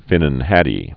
(fĭnən hădē)